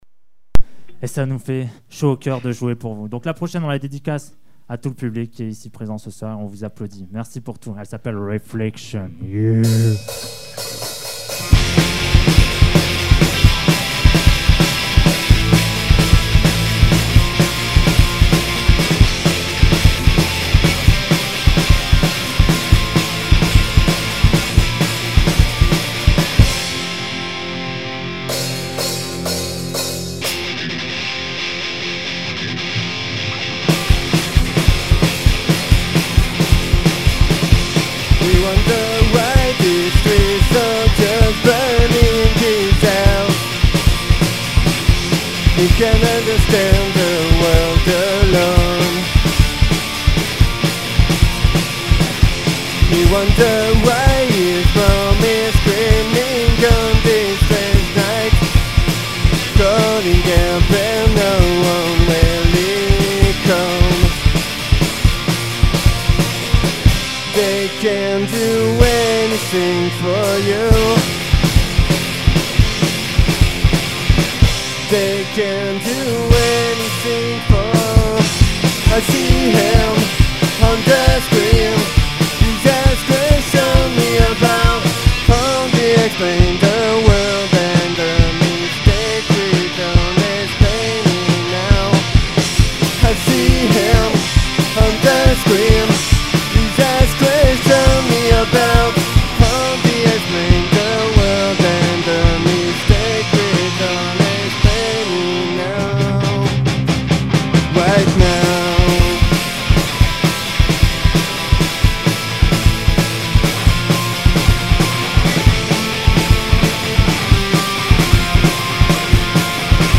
Live au Biplan